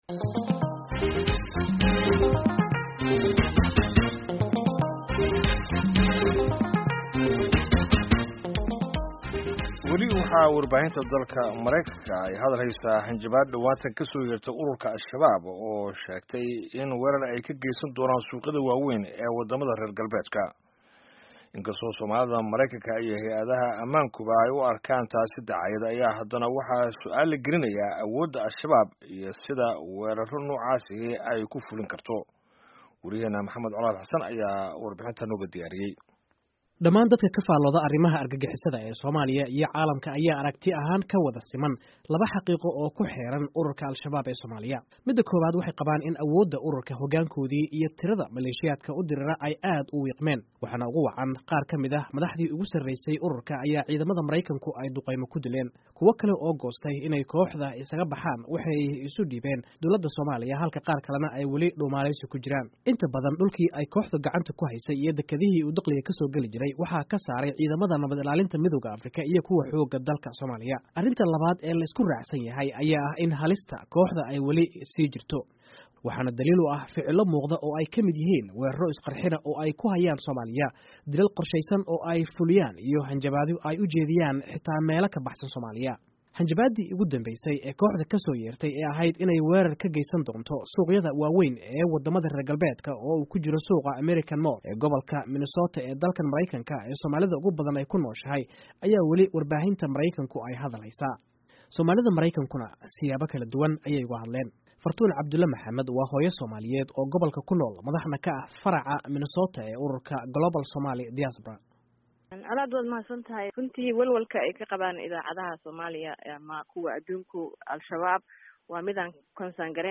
Warbixin ku Saabsan Hanjabaadda Al-shabab